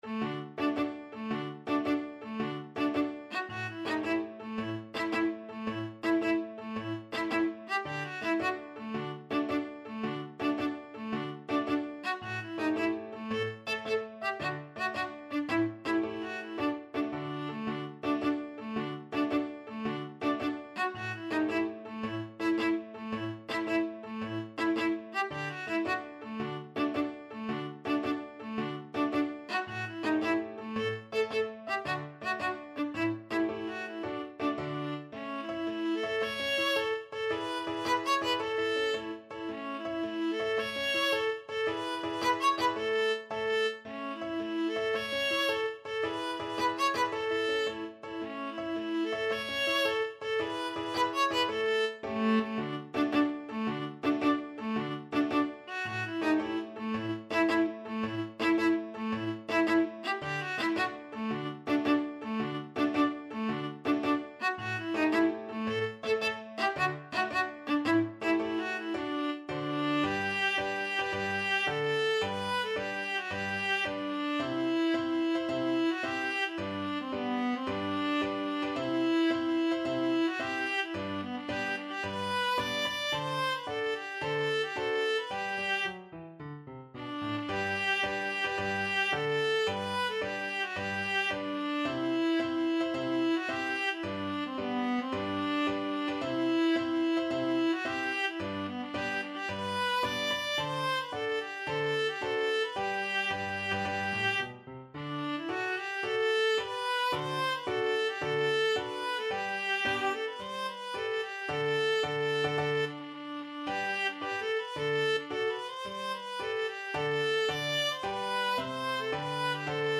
ViolaViola
D major (Sounding Pitch) (View more D major Music for Viola )
6/8 (View more 6/8 Music)
Classical (View more Classical Viola Music)